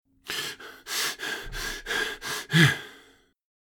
Download Free Breathing Sound Effects | Gfx Sounds
Aged-man-heavy-labor-breaths.mp3